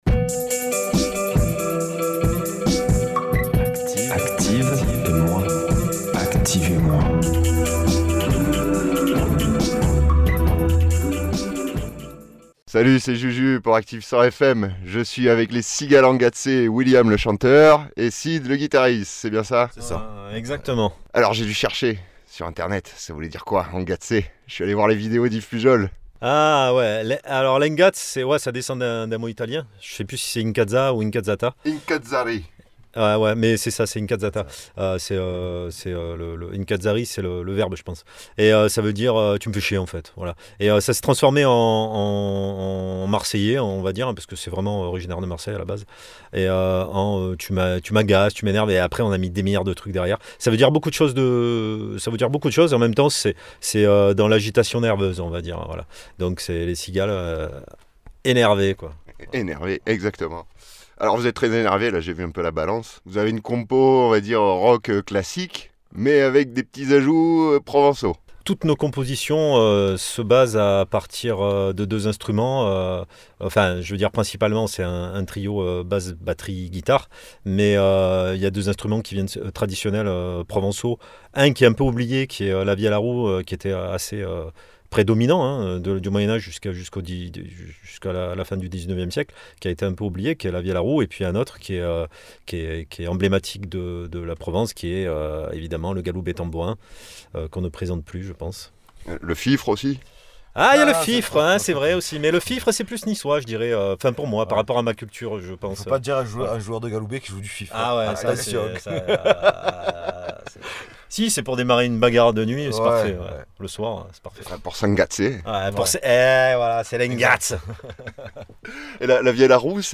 Mêlant les chants traditionnels de la Provence à du Punk énergique, ils vous feront vous guingasser toute la nuit.